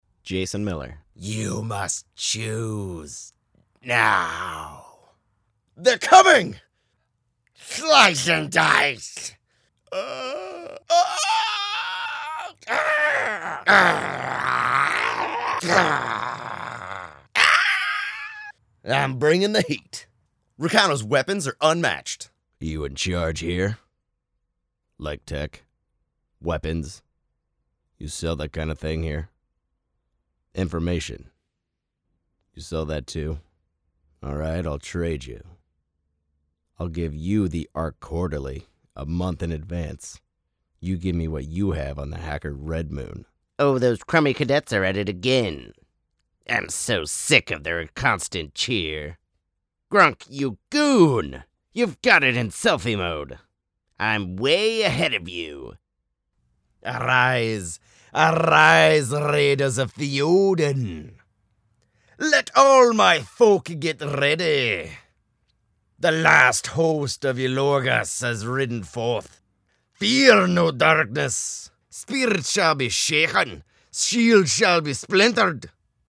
Adult, Young Adult
Location: Los Angeles, CA, USA Languages: english 123 Accents: standard us Voice Filters: VOICEOVER GENRE gaming promos NARRATION FILTERS friendly